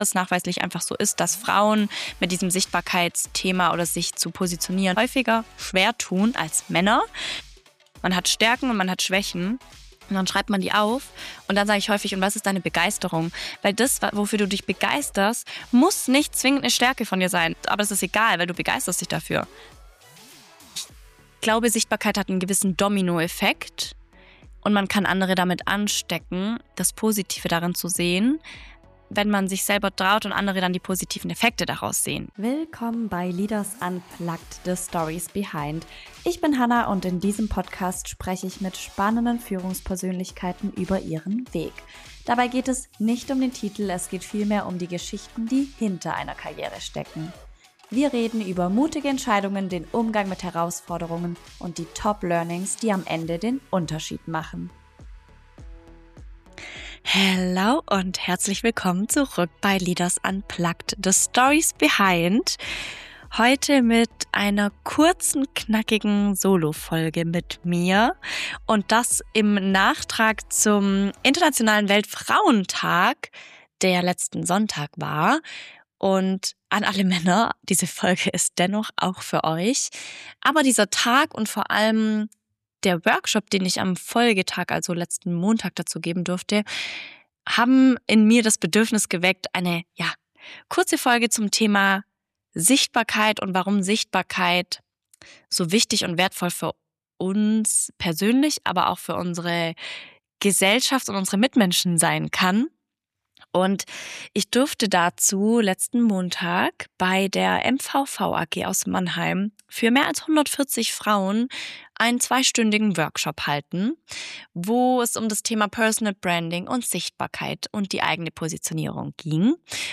#018 Solofolge zu Personal Branding – Wer willst du sein in einer Welt, in der du alles sein kannst? ~ Leaders unplugged - the stories behind Podcast
Warum fällt es vielen Menschen – besonders Frauen – schwer, sich sichtbar zu machen? In dieser Solofolge spreche ich über Personal Branding, Positionierung, den Mut sich zu zeigen und die Frage, warum Sichtbarkeit mehr sein kann als reine Selbstdarstellung.